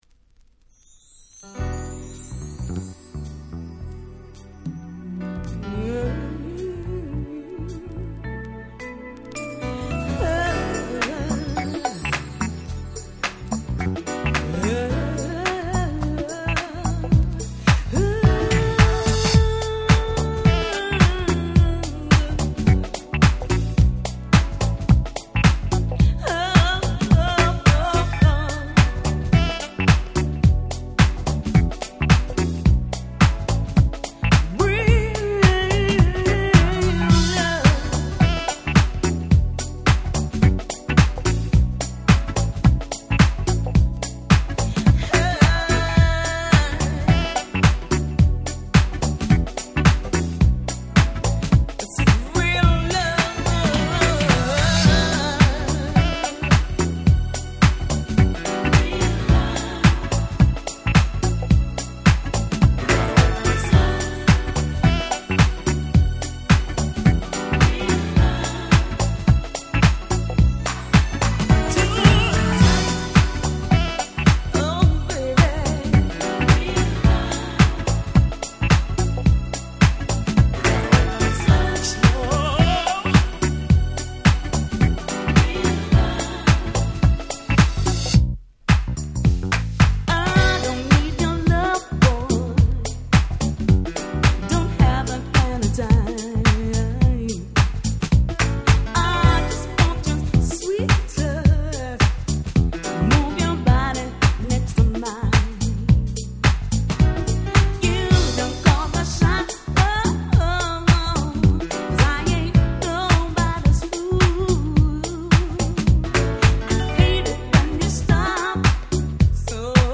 UK R&B Classics !!
定番中の定番。爽やか〜